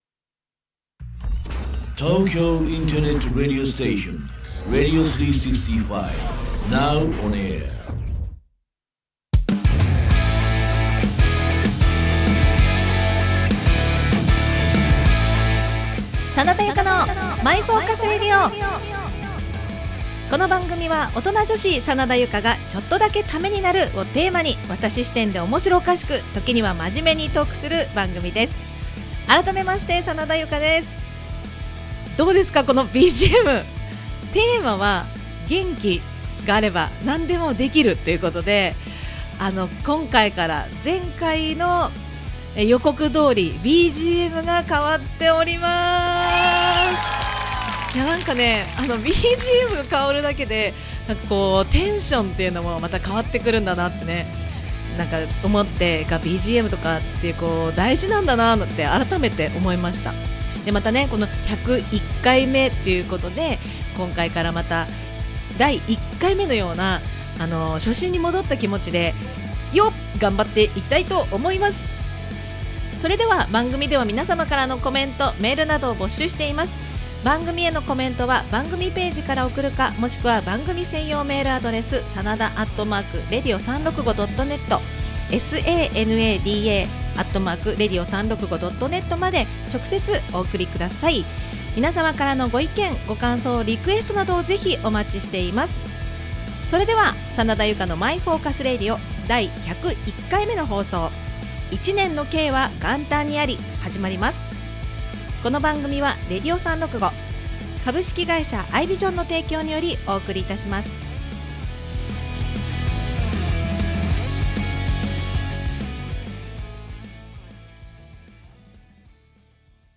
今回からなななんと！！BGMが変わって気分アゲアゲでお送りします！